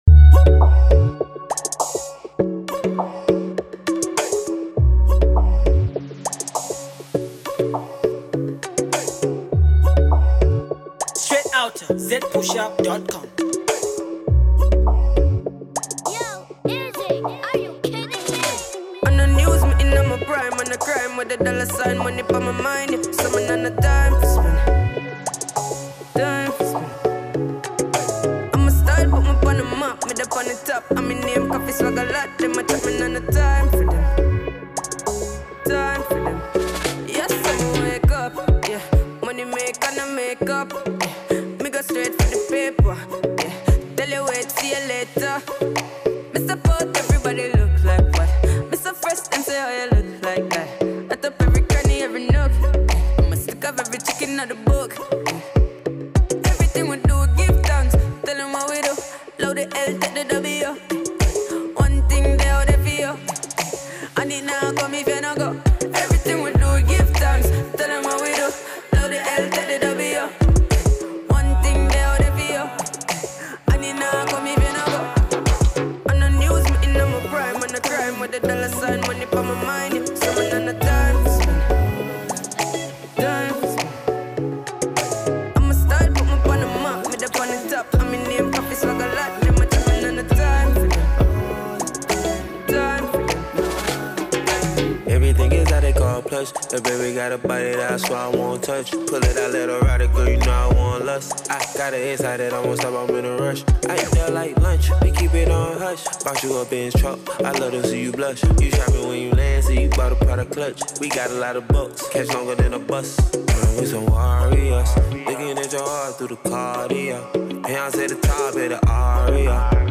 WORLD MUSIC
Jamaican Reggae super talented artist